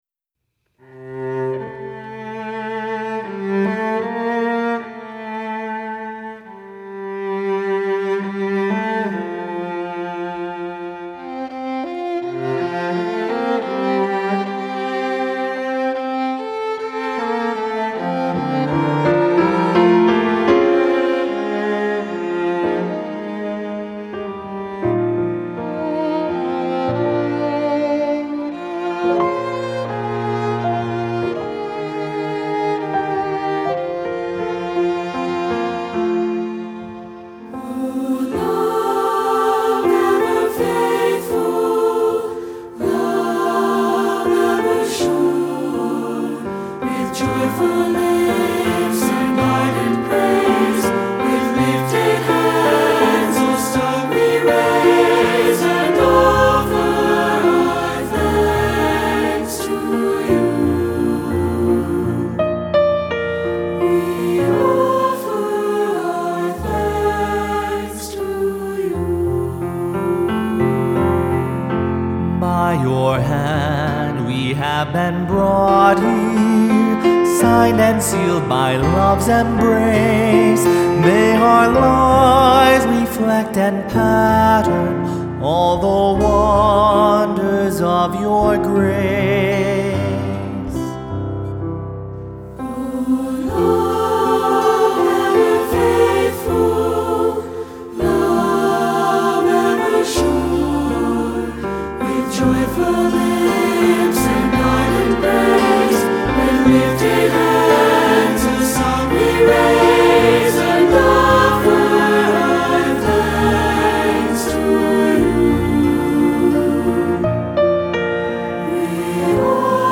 Voicing: SAB; Cantor; Assembly